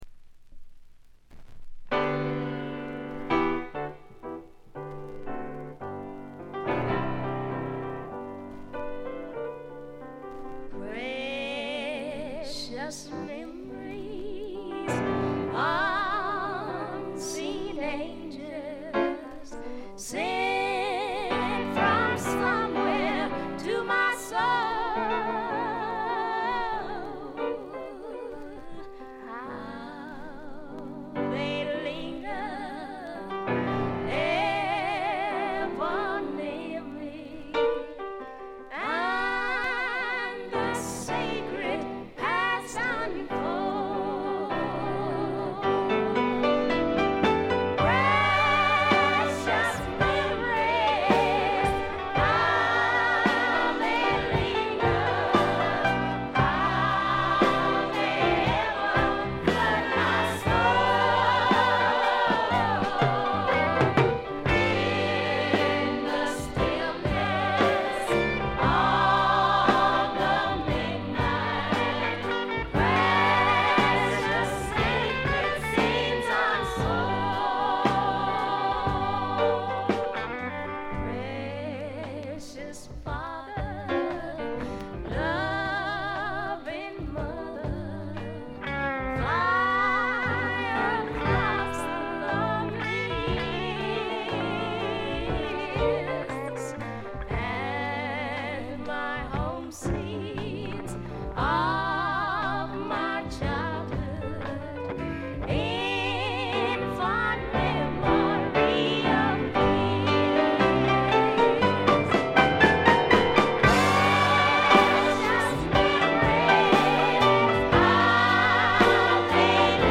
微細なバックグラウンドノイズ程度。
知る人ぞ知るゴスペル・スワンプの名作！
リードシンガーは男２、女２。
試聴曲は現品からの取り込み音源です。